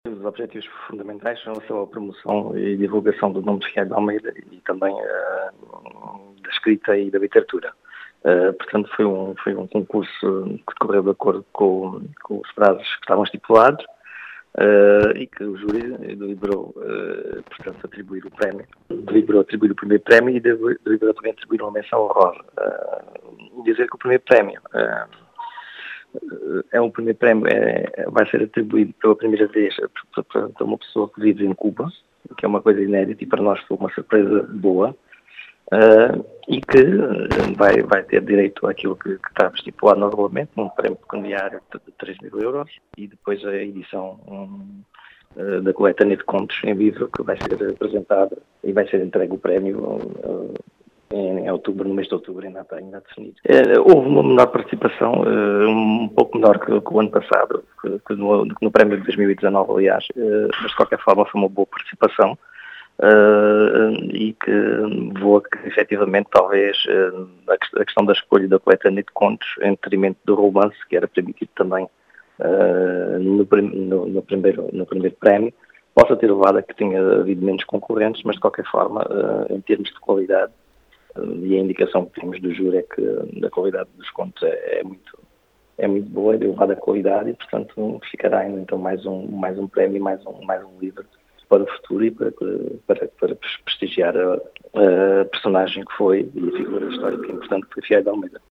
As explicações são do presidente da Câmara de Cuba, João Português, que deixou os objetivos deste concurso.